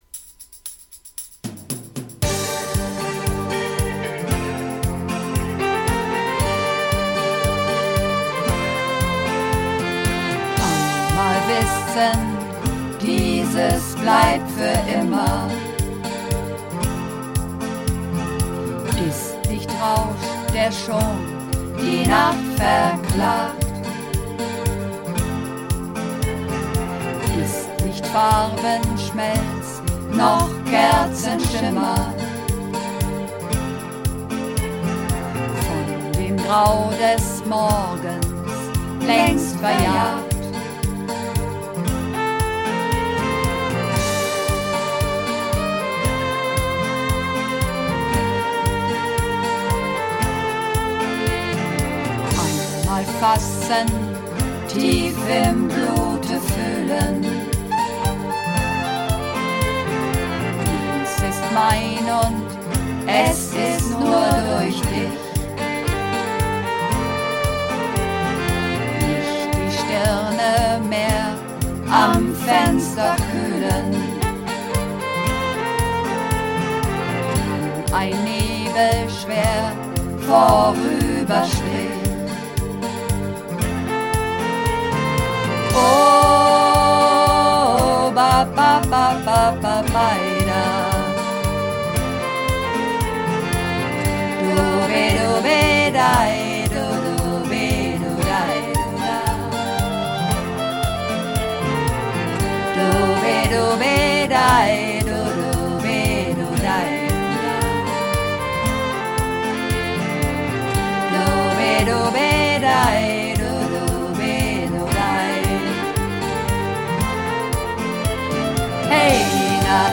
Übungsaufnahmen - Am Fenster
Am Fenster (Mehrstimmig)
Am_Fenster__5_Mehrstimmig.mp3